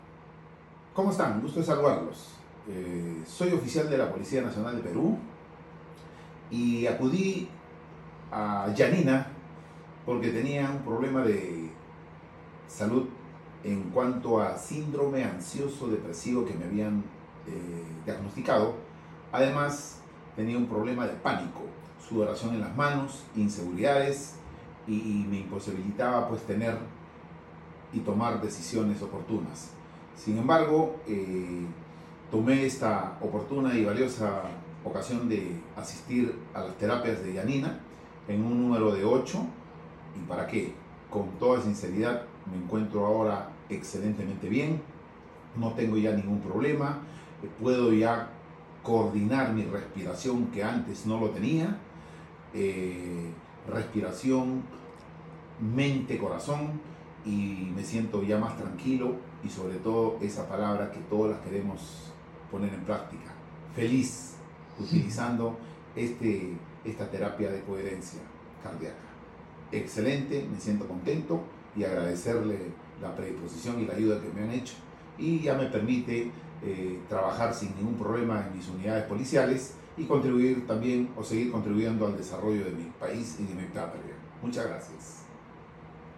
Testimonio General de la Policía del Perú